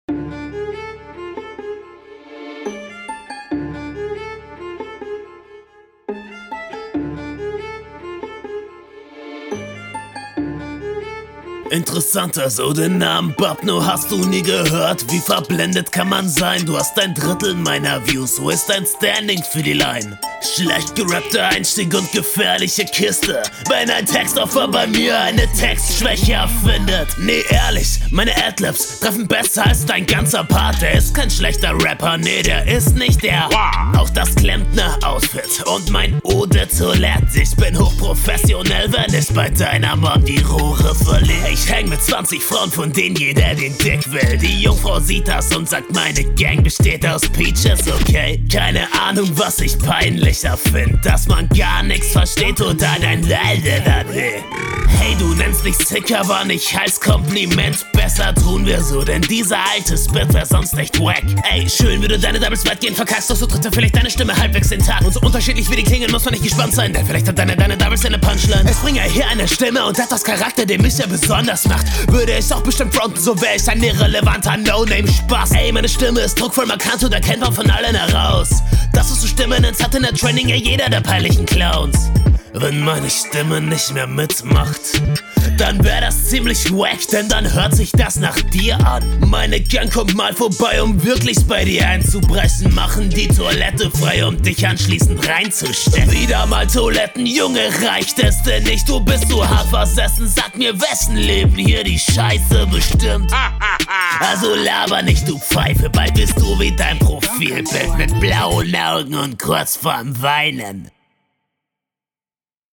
Flow: Flowlich traust du dich hier leider zu wenig.